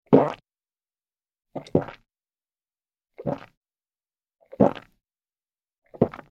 Звуки глотка
Звук кадыка при глотании от сильного переживания